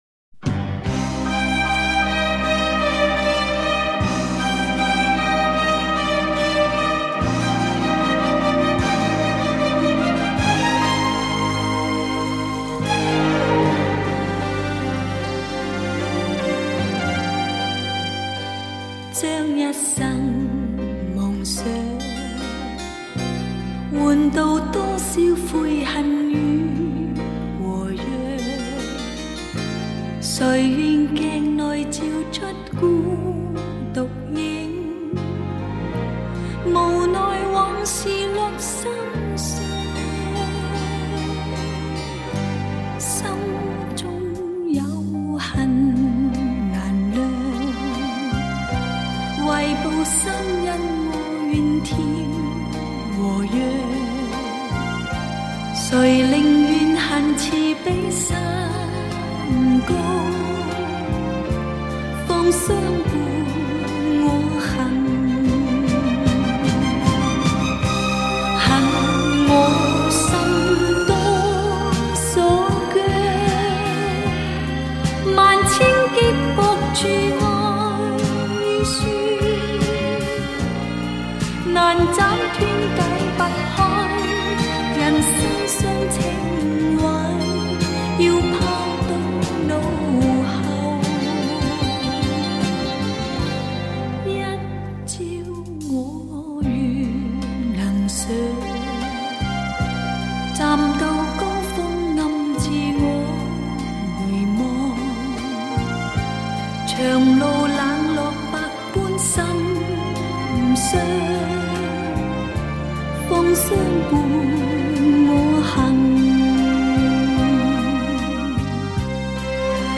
圆润清晰 极具魅力
无损音乐